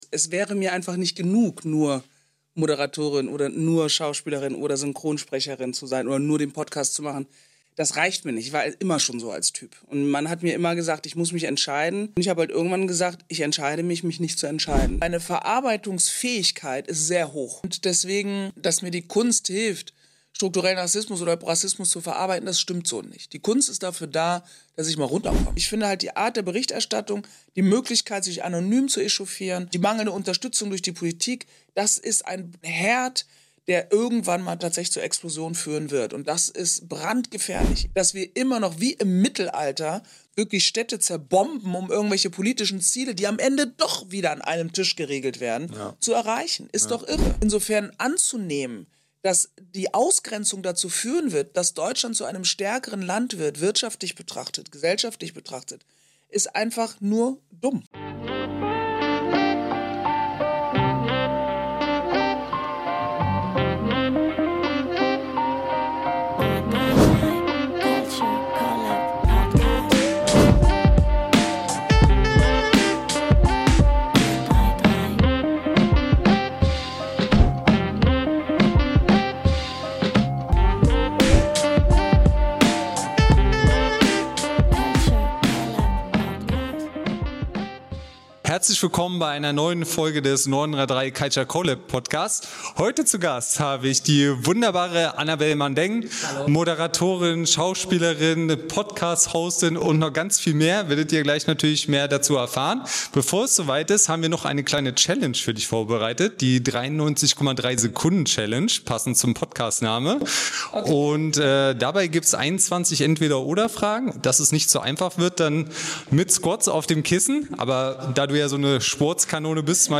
_____________________________________________________  Bei 933 CULTURE CO:LAB trifft Culture auf Mindset – echte Gespräche mit kreativen Köpfen, die Großes bewegen.